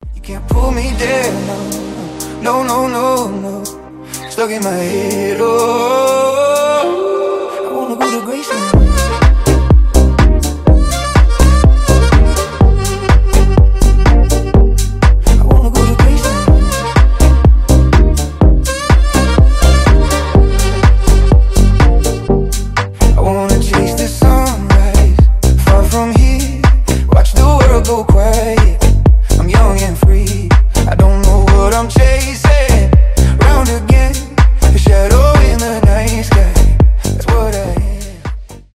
Танцевальные рингтоны
саксофон
house
заводные